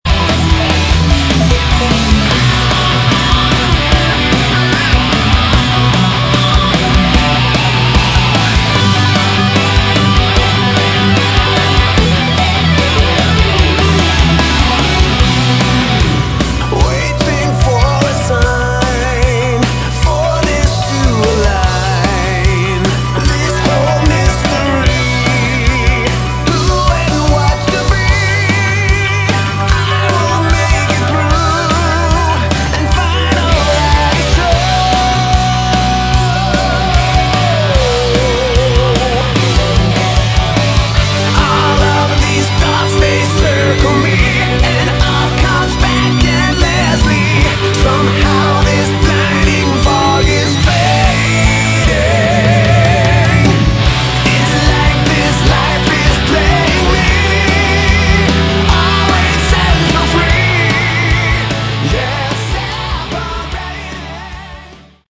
Category: Melodic Rock / AOR
vocals, backing vocals
guitar, bass, synthesizer, backing vocals
drums, percussion
keyboards
piano
flute, backing vocals